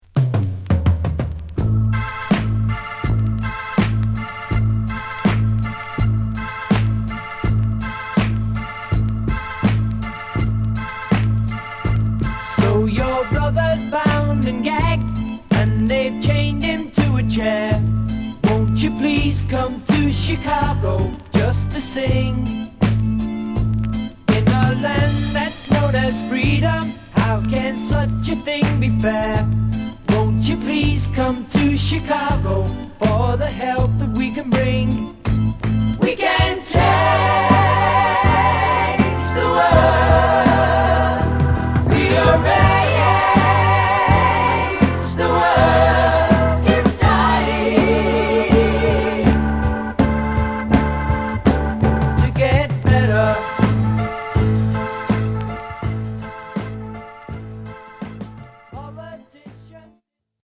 マイナーコードで淡々と進むベースに不穏なオルガンのリフが乗るスリリングなFunky Rock！